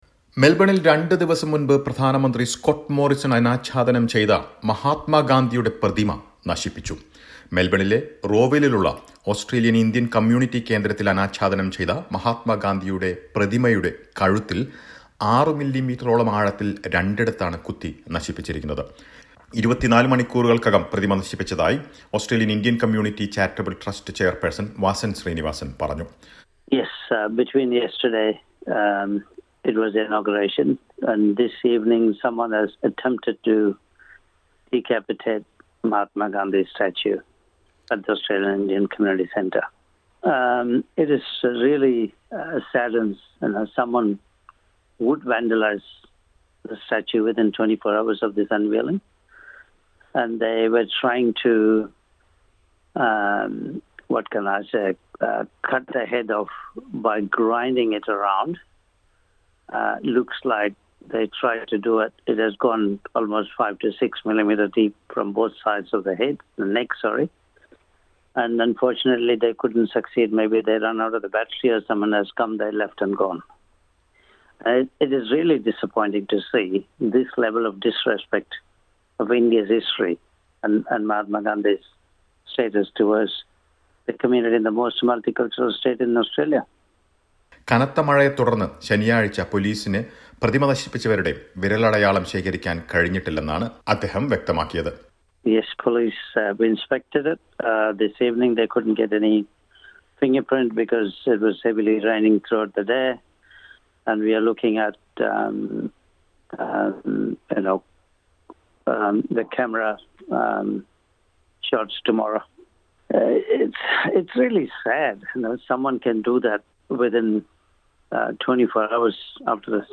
Mahatma Gandhi's statue unveiled in Melbourne on Friday has been vandalised within 24 hours. Listen to a report.